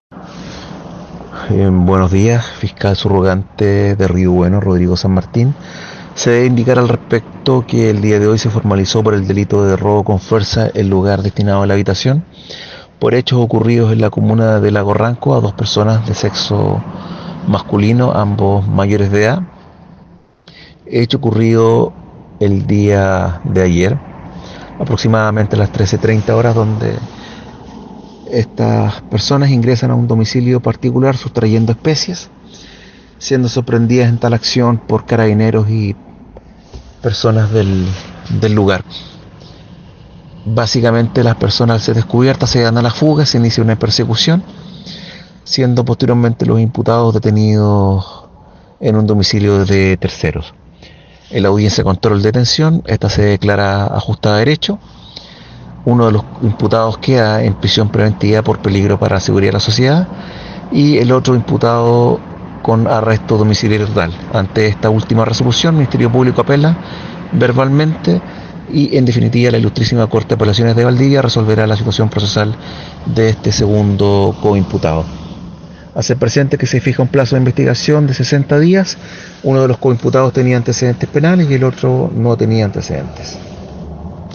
Fiscal Rodrigo San Martín …